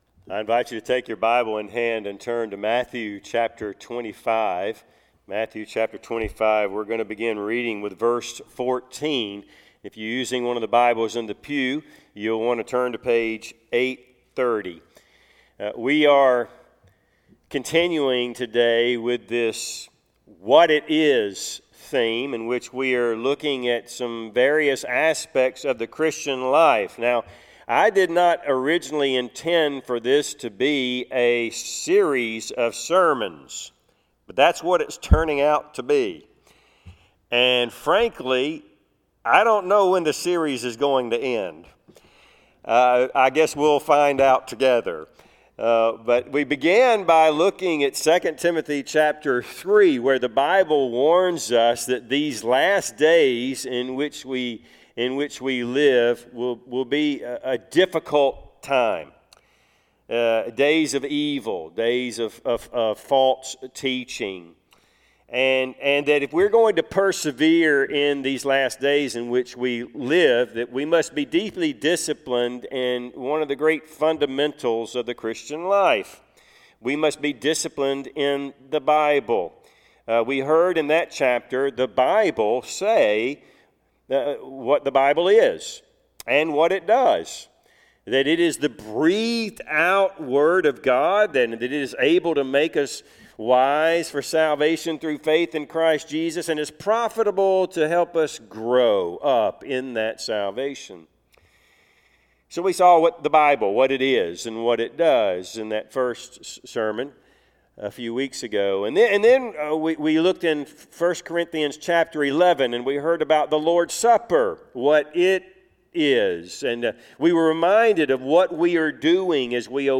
What It Is Passage: Matthew 25:14-30 Service Type: Sunday AM Topics